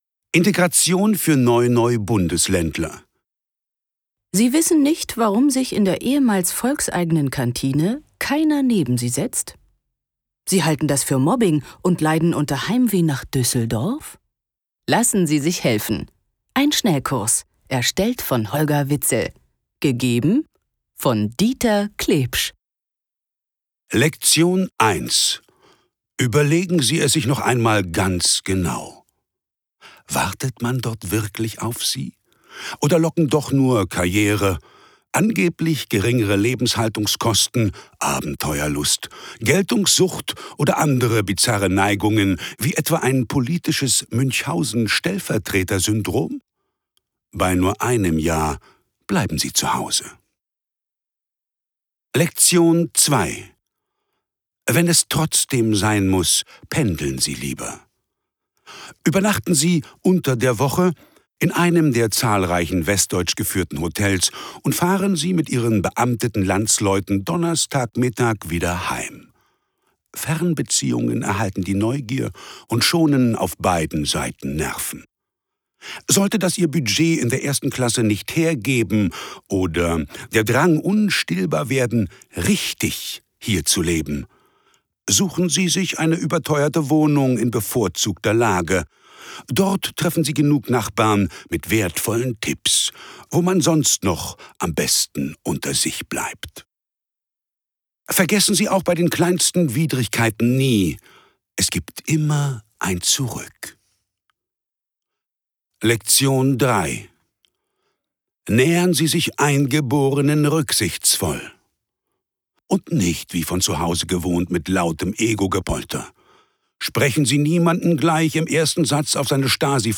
Schlagworte BRD • DDR • Deutschland • Hörbuch; Literaturlesung • Kolumnen • Neue Bundesländer; Humor • Neue Bundesländer / Ostdeutschland; Humor • Ossi • Wessi • Wiedervereinigung • Zonenlümmel